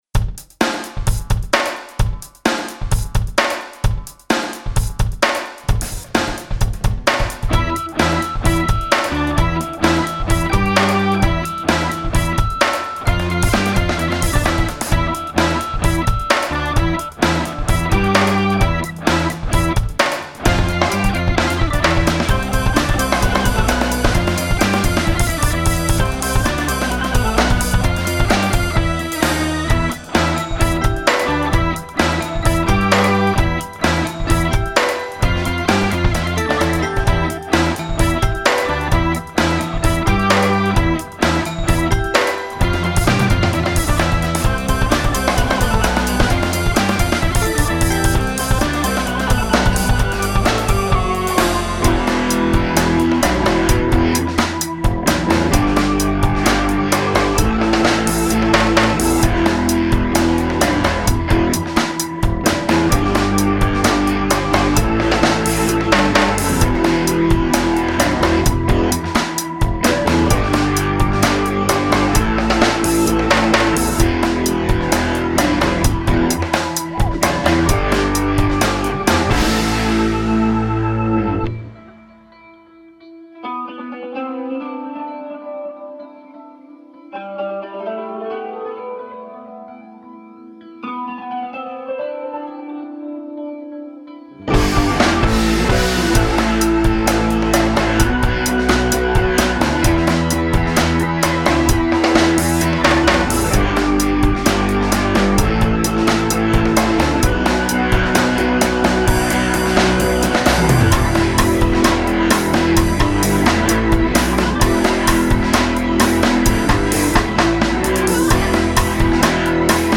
В конце концов, борясь с собой, решил, что лучше без слов...